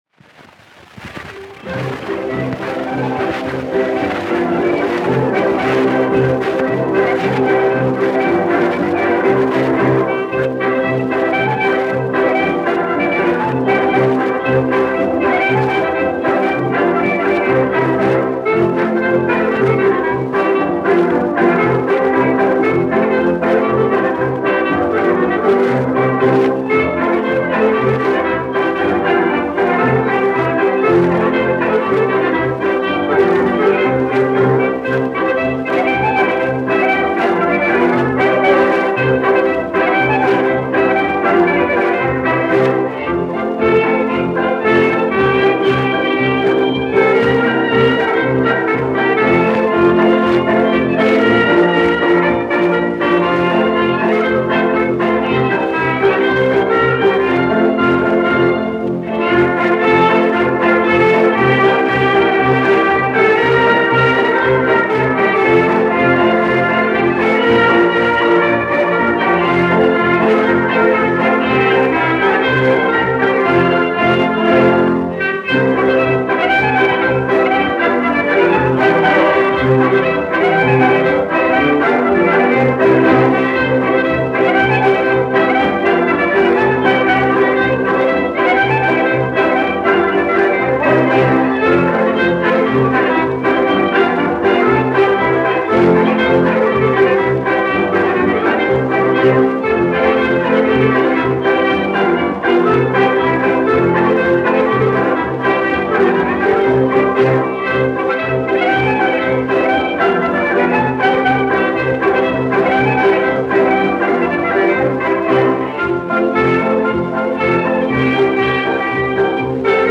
1 skpl. : analogs, 78 apgr/min, mono ; 25 cm
Polkas
Latvijas vēsturiskie šellaka skaņuplašu ieraksti (Kolekcija)